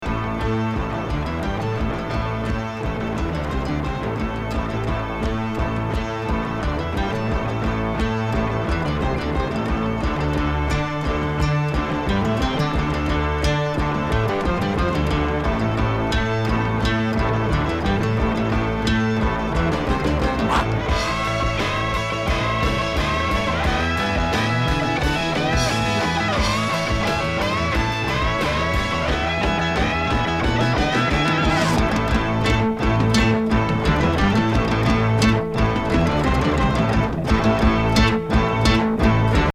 2300回も（！）オーバーダビングされて緻密に作りこまれたロマンチック脳内旅行大作。